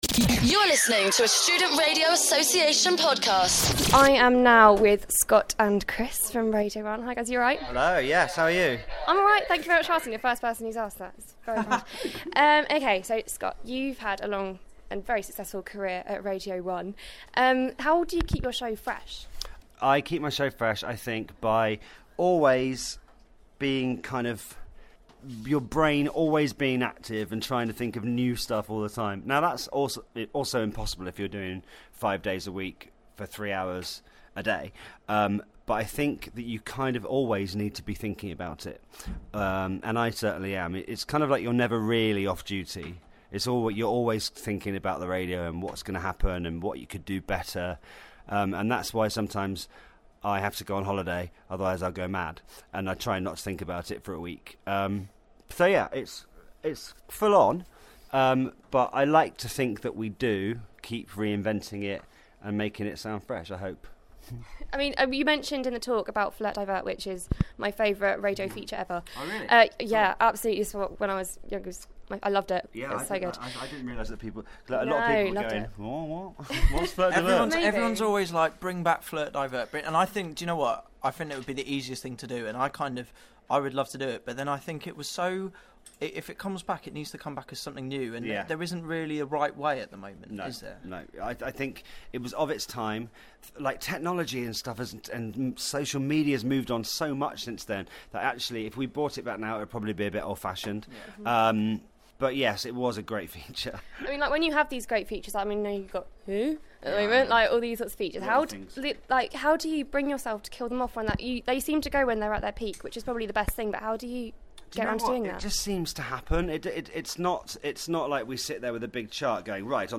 Interview with BBC Radio 1's Scott Mills and Chris Stark